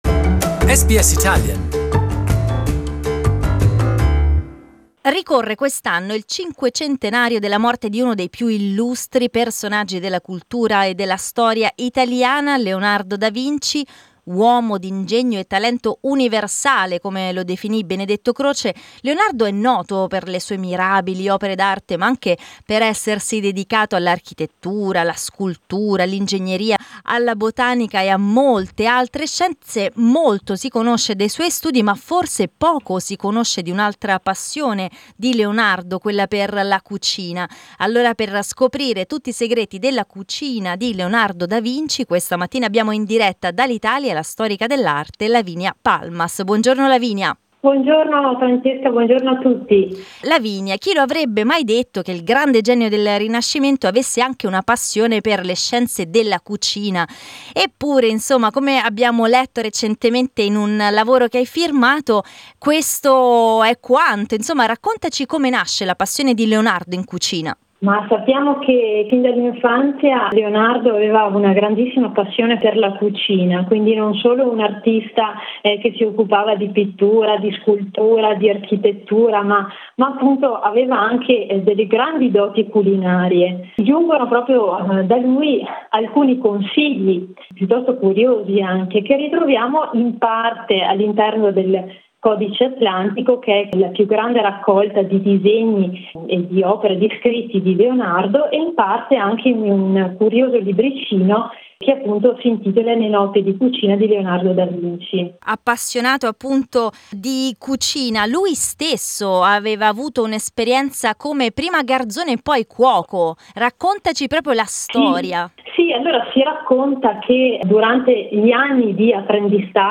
come ci ha raccontato in questa intervista la storica dell'arte italiana